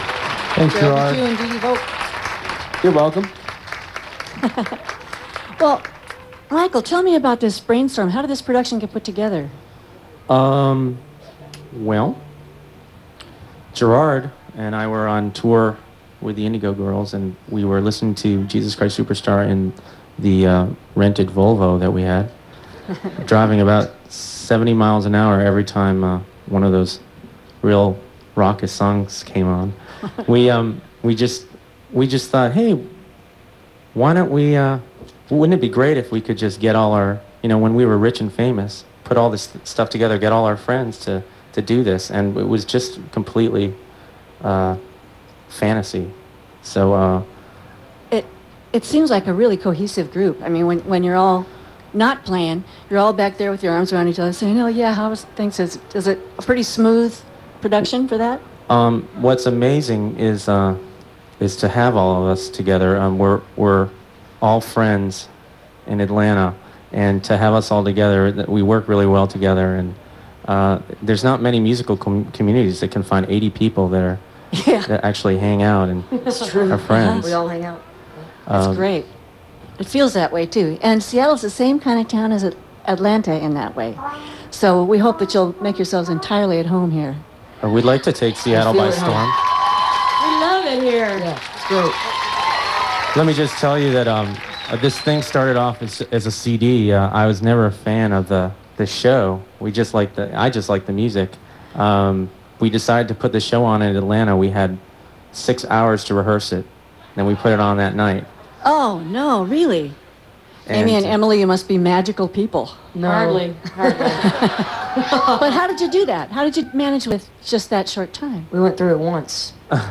05. interview (4:38)